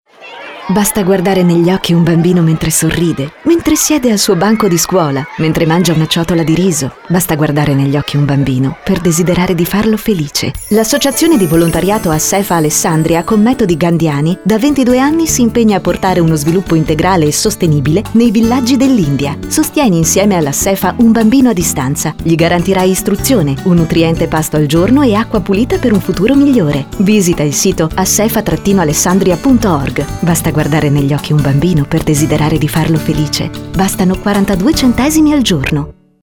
Spot radiofonico per ASSEFA Alessandria